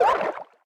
Sound effect of Swim in Super Mario 3D World.
SM3DW_Swim.oga